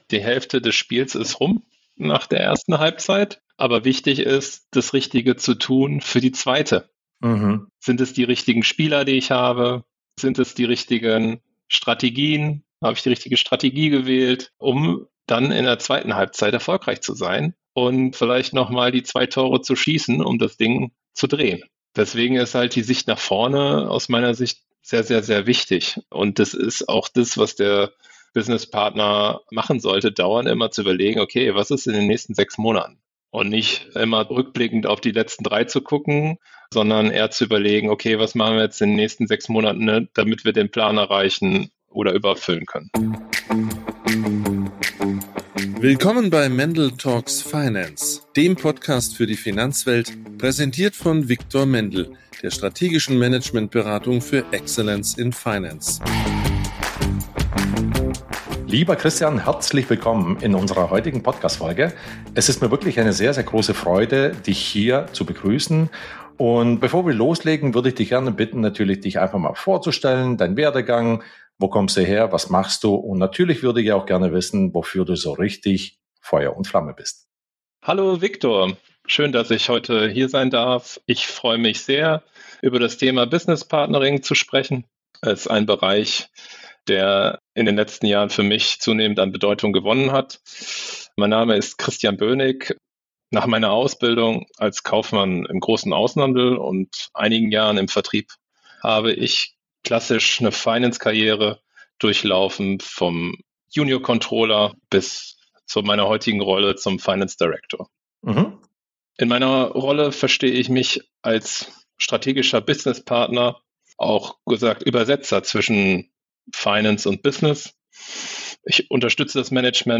Und: Er übersetzt Zahlen in Geschichten, die Entscheider verstehen. Sein Erfolgsrezept: • Nähe zum Business schlägt jede Analyse • Vertrauen entsteht vor dem Meeting – nicht im Reporting • Business Partnering heißt: gemeinsam steuern, nicht kontrollieren Ein Gespräch über Klarheit, Wirkung und die zweite Halbzeit.